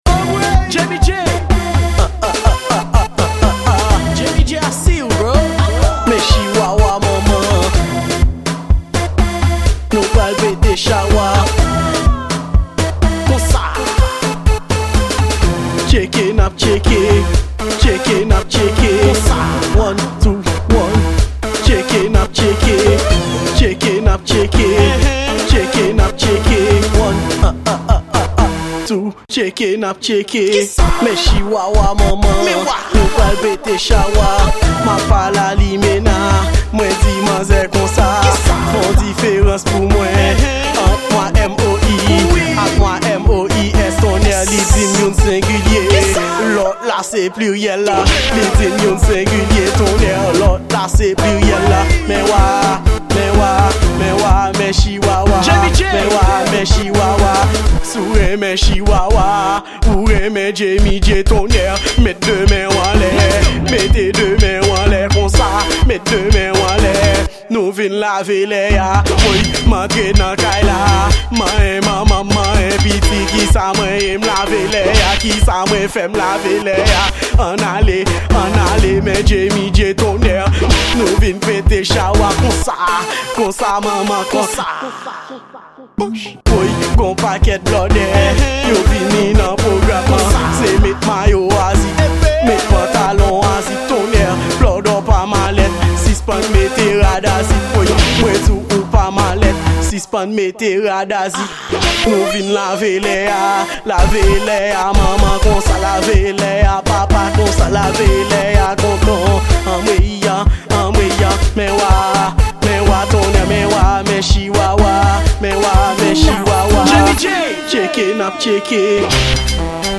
Genre: Raboday